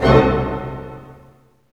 HIT ORCHD06L.wav